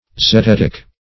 zetetic - definition of zetetic - synonyms, pronunciation, spelling from Free Dictionary
Zetetic \Ze*tet"ic\, a. [Gr.